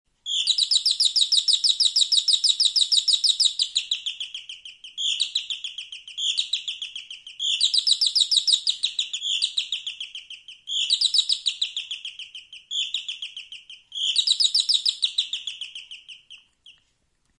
Canario (Serinus canaria domestica)